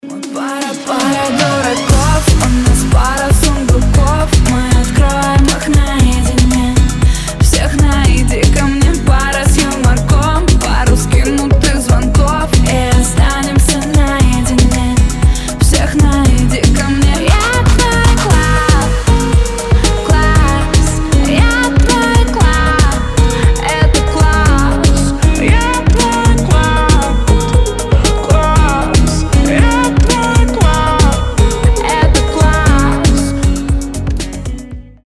• Качество: 320, Stereo
поп
гитара
женский вокал
милые
романтичные